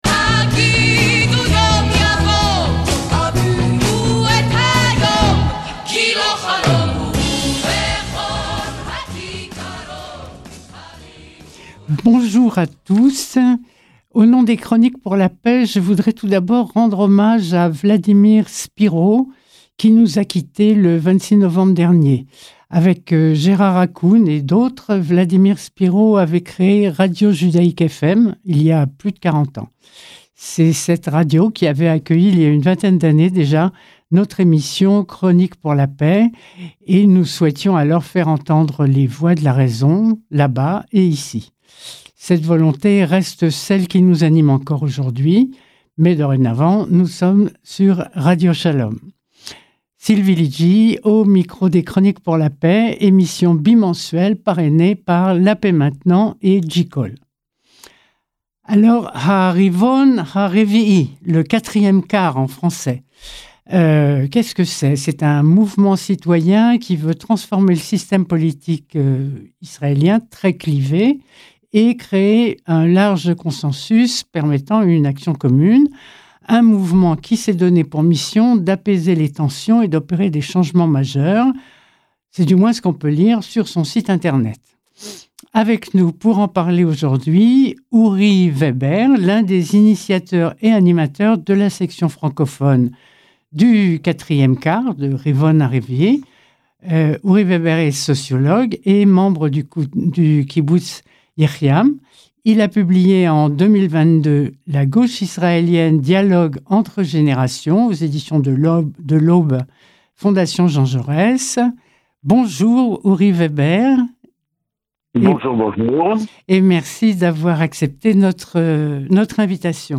CLIQUER SUR LA FLÈCHE (LIEN) CI-DESSOUS POUR ÉCOUTER L’INTERVIEW SUR RADIO SHALOM (94.8 fm)